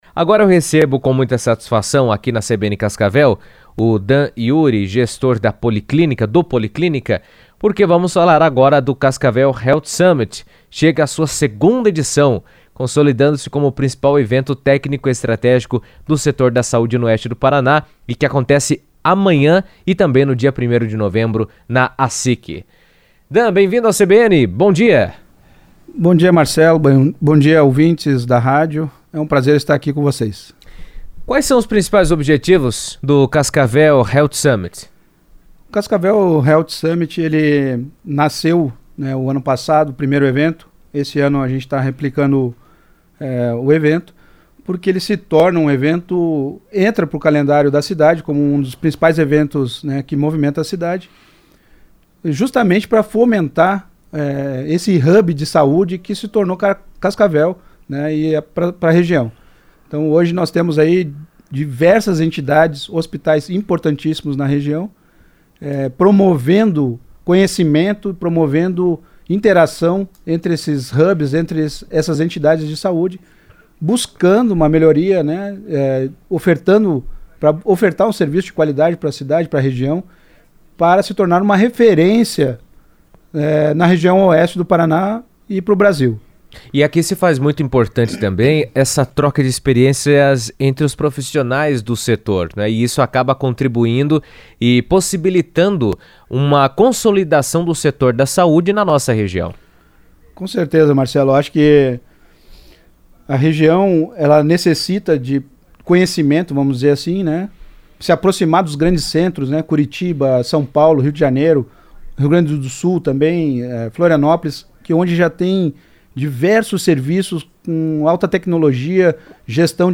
Durante a entrevista, ele destacou a importância do encontro para debater tendências, compartilhar boas práticas, fortalecer conexões institucionais e impulsionar o desenvolvimento sustentável do setor.